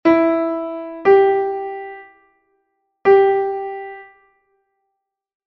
Listen to the sound file and write the notes you hear (E or G for notes and R for rests).
e_g_4_notes_and_rests.mp3